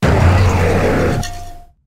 roaringmoon_ambient.ogg